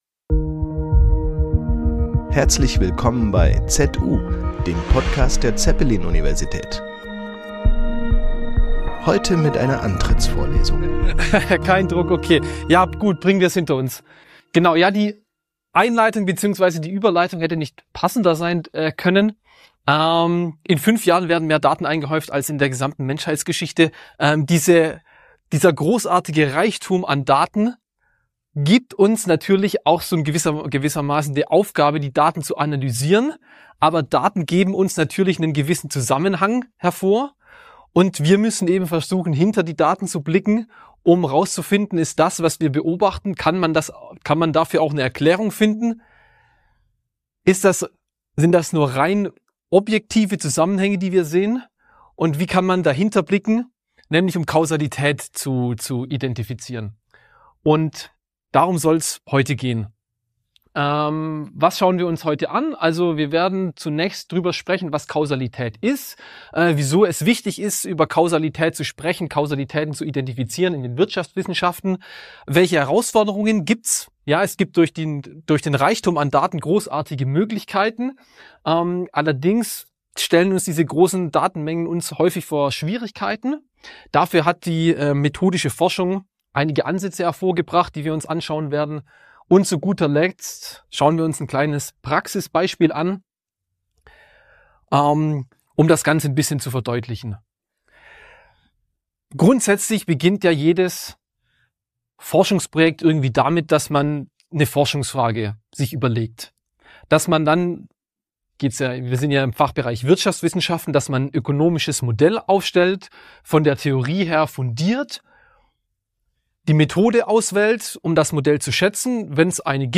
Antrittsvorlesung ~ ZU